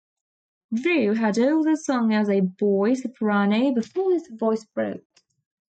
Pronounced as (IPA) /dɹuː/